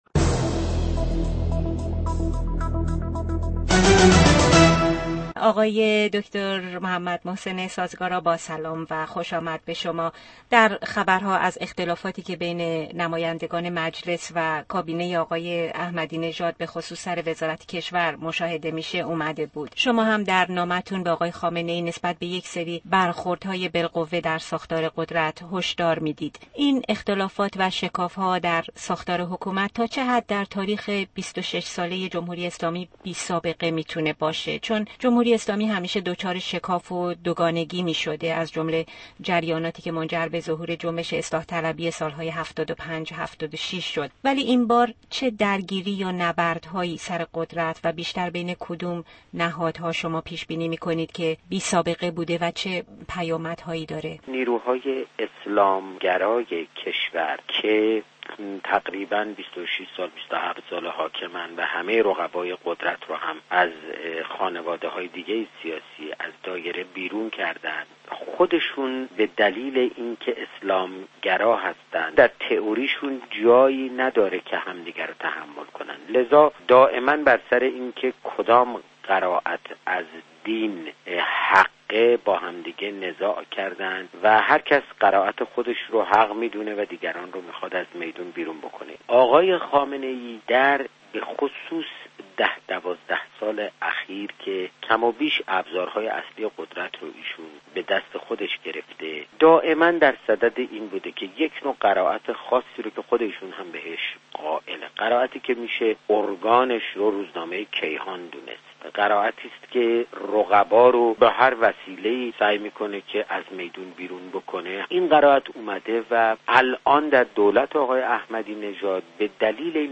محسن سازگارا - مصاحبه‌ راديو اس بی اس استراليا با محسن سازگارا October 15, 2005
Interview with Dr. Sazegara : 15.10.2005